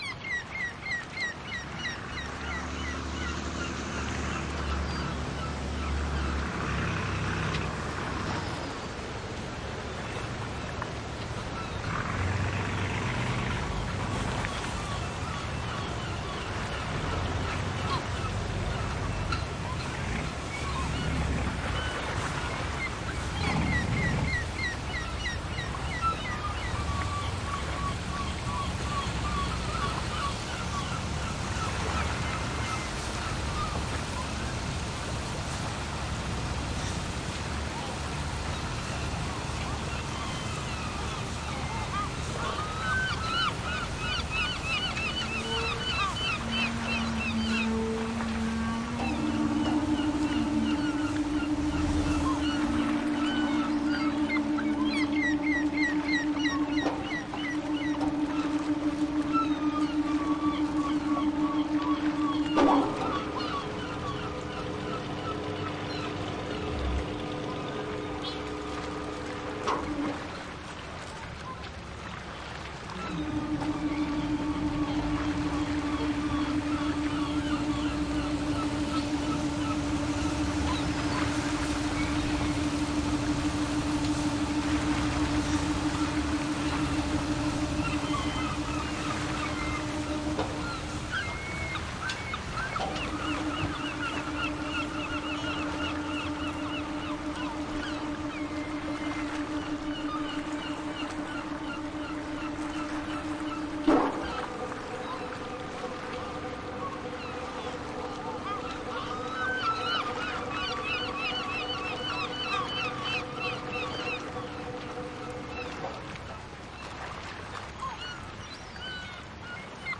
自然-海鸥.wav